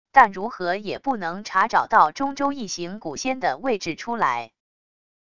但如何也不能查找到中洲一行蛊仙的位置出来wav音频生成系统WAV Audio Player